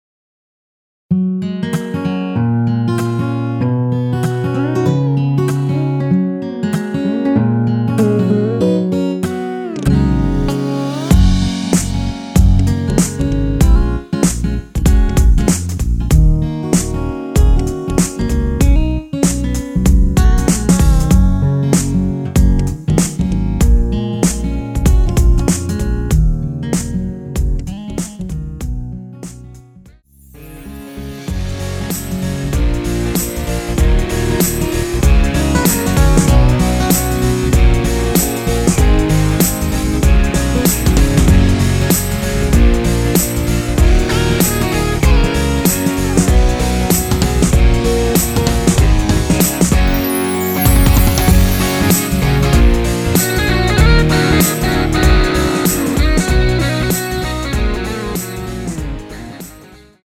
원키에서(-2)내린 MR입니다.
Gb
앞부분30초, 뒷부분30초씩 편집해서 올려 드리고 있습니다.
중간에 음이 끈어지고 다시 나오는 이유는